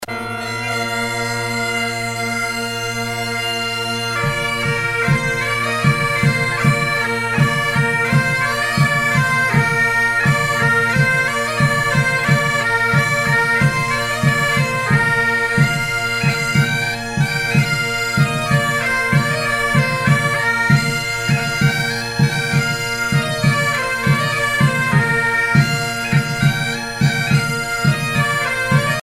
danse : polka piquée
Pièce musicale éditée